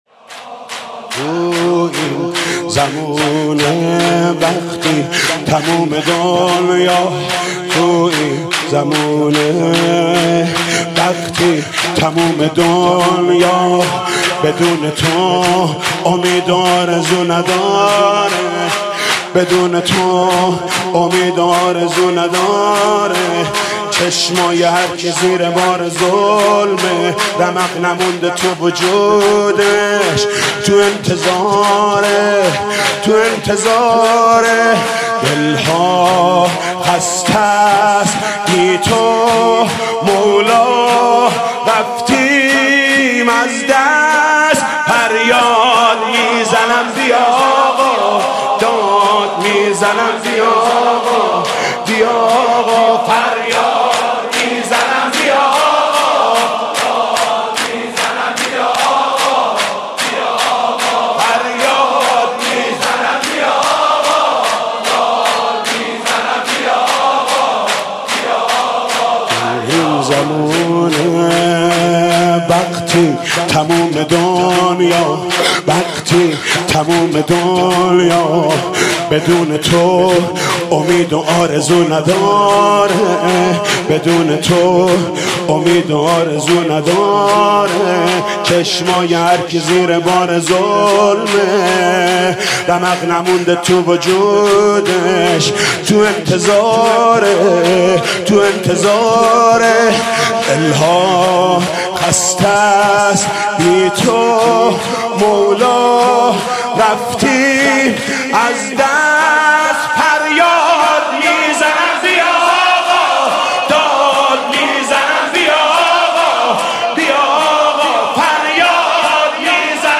شب دوم فاطمیه اول 1437 | هیات رایه العباس | حاج محمود کریمی
زمین و زمون بی تاب و مضطر | زمینه | حضرت زهرا سلام الله علیها       [ حجم فایل 6.7 مگابایت ]